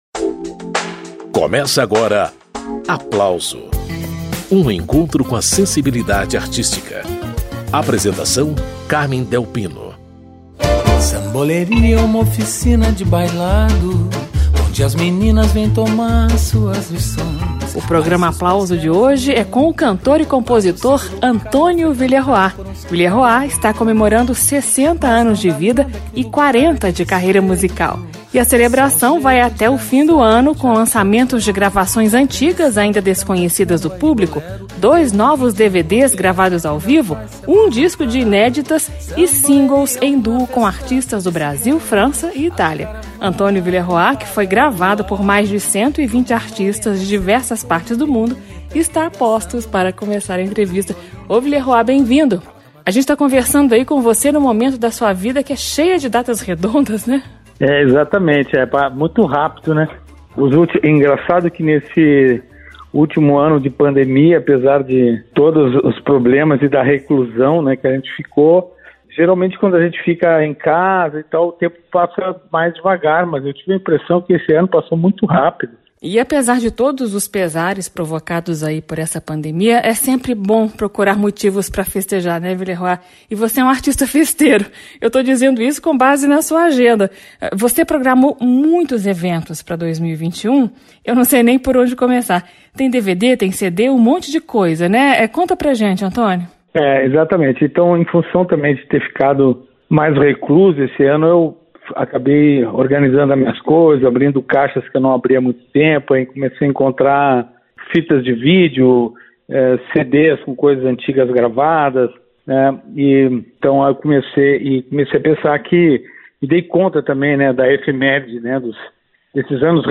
O programa Aplauso desta semana recebe o cantor, compositor, instrumentista e produtor musical Antonio Villeroy.